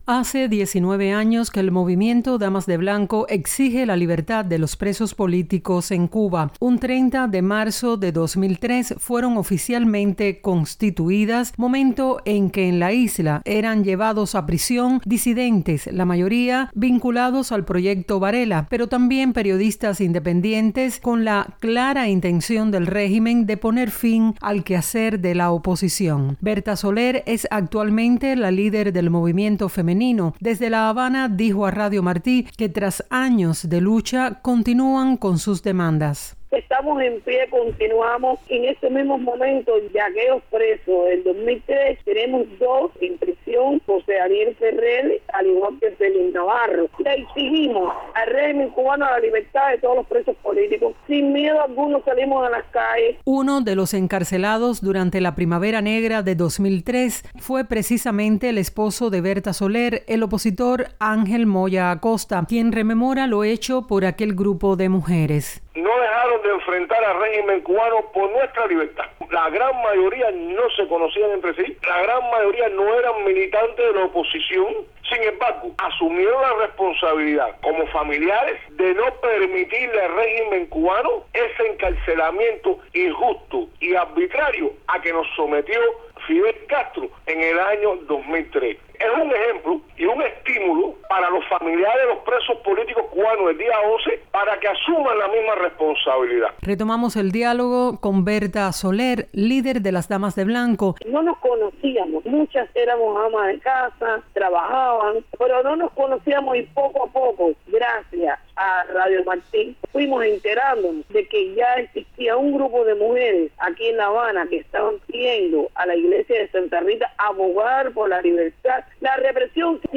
Líder de las Damas de Blanco habla de los inicios de la organización